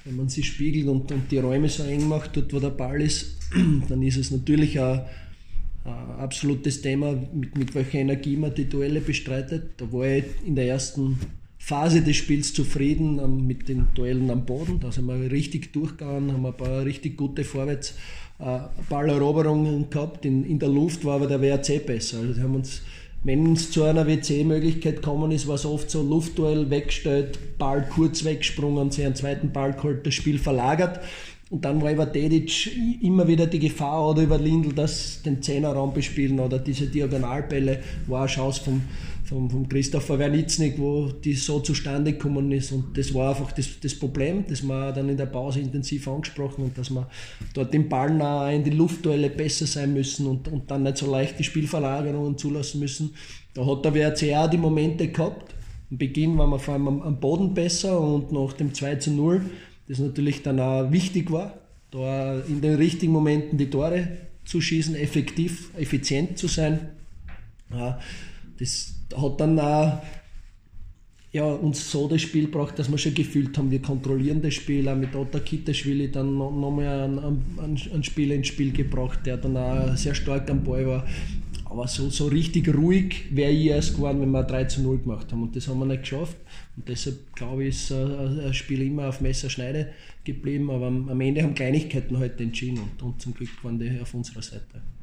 Stimmen: Wolfsberger AC vs. SK Sturm Graz